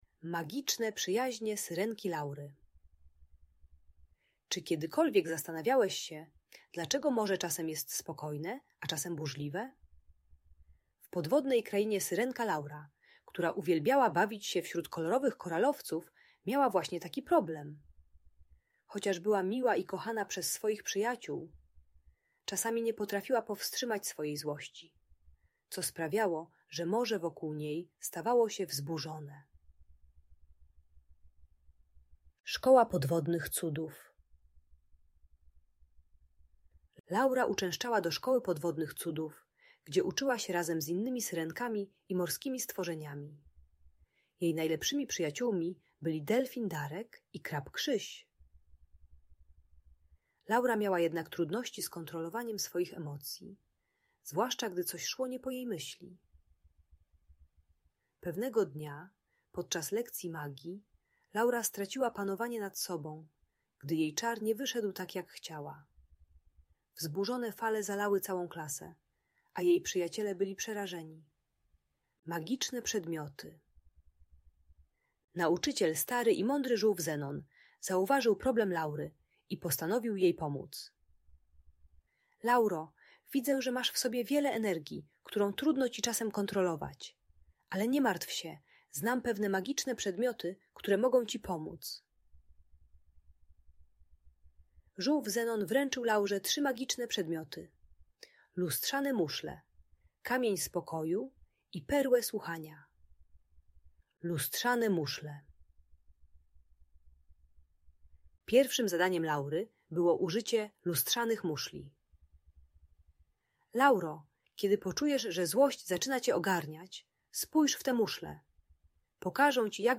Magiczne Przyjaźnie Syrenki Laury - Bajkowa Story - Audiobajka